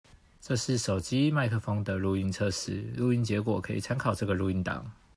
根據我實測結果我的看法是～我只能說抗噪確實不錯各位可以聽看看我錄製的 4 組參考錄音檔，有背景音樂的部分我故意找之前做有破音的音樂來測試
▶ 無背景音樂錄音檔
● 手機收音